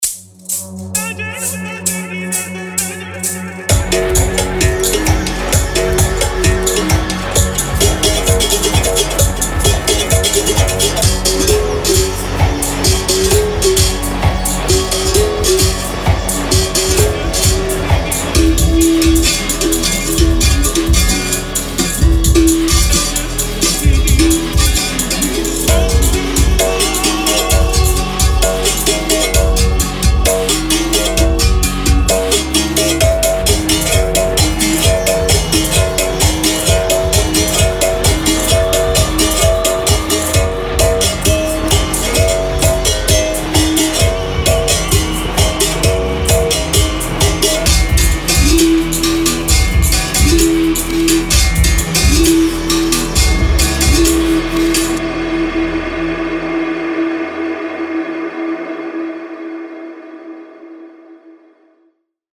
Instruments
BerimbauOrganic Loops は、珍しく魅力的な楽器であるベリンバウに焦点を当てたコレクションを誇りをもってお届けします。アフリカに起源を持ち、ブラジルのカポエイラ音楽で広く用いられるこのユニークな音色のコレクションは、ジャンルを問わずあなたのトラックにぴったりと馴染みます。
Berimbau には、ループとワンショットが混在しており、ベリンバウのサンプルに加えて、追加のカシシパーカッションやコンボループも収録されています。
詳細としては、71MB のコンテンツが含まれ、すべてのオーディオは 24Bit 44.1kHz で録音されています。